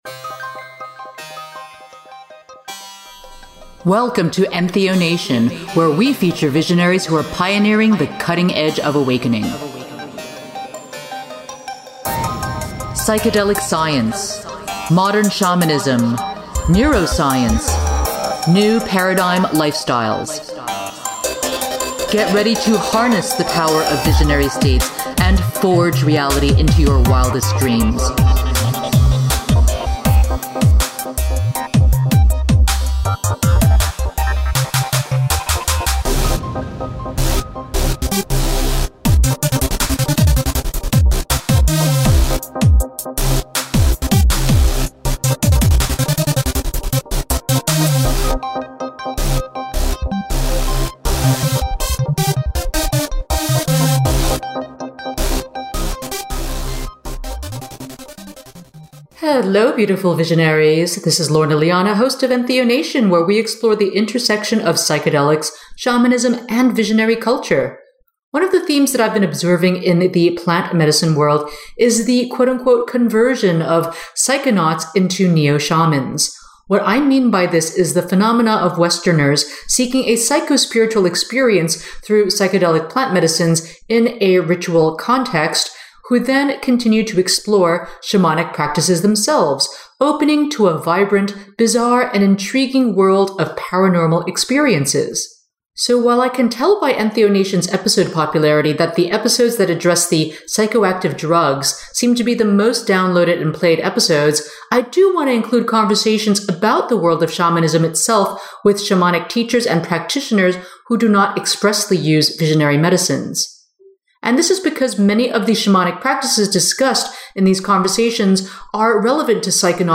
Today’s episode is a conversation